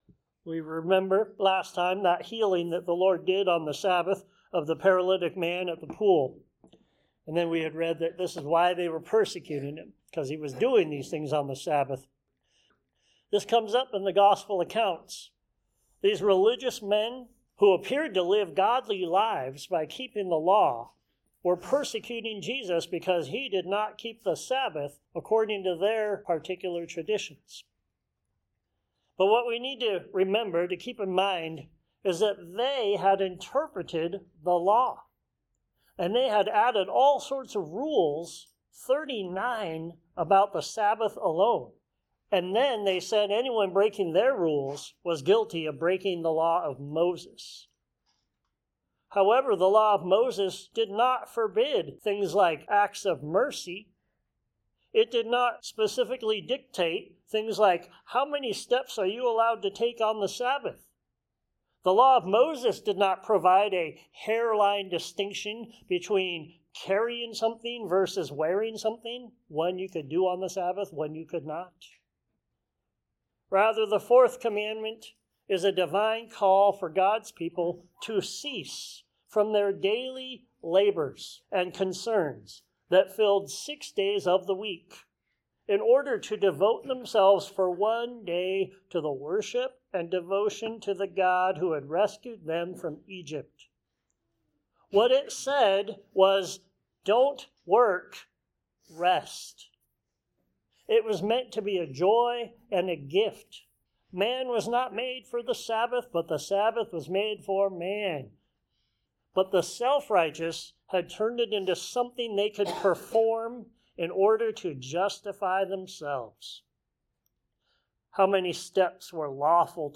Judge Jesus John 5:16-47 Sermons Share this: Share on X (Opens in new window) X Share on Facebook (Opens in new window) Facebook Like Loading...